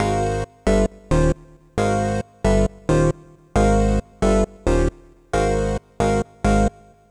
Jfx Synth.wav